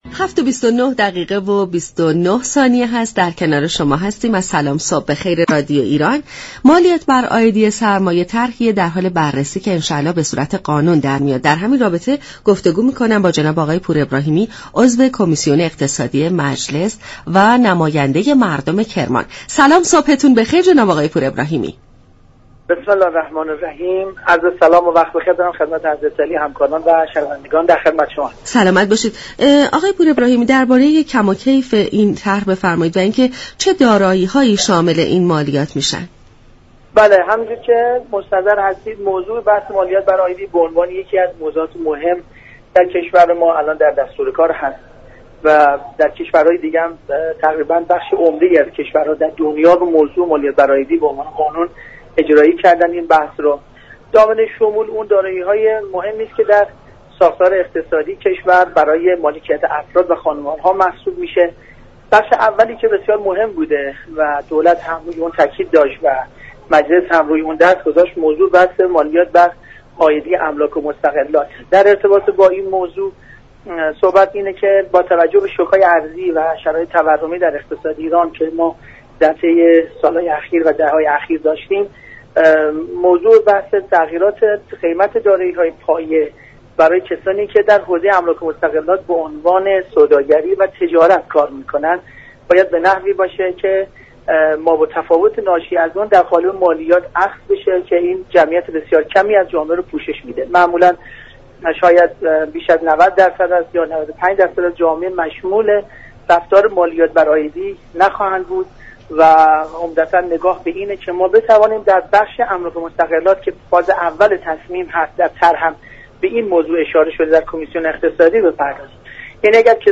محمدرضا پور ابراهیمی عضو كمیسیون اقتصادی مجلس در گفت و گو با برنامه «سلام صبح بخیر» درباره طرح مالیات بر عایدی سرمایه گفت: طرح مالیات بر عایدی سرمایه كه هم اكنون به عنوان موضوع مهم در دستور كار مجلس قرار گرفته در بیشتر نقاط دنیا شكل قانونی پیدا كرده است.